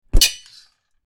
Sword Clash
Sword_clash.mp3